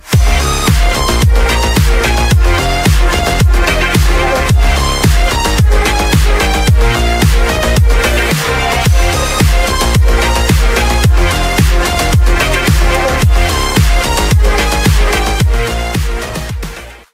• Качество: 192, Stereo
Ритмичный бит звучит после матча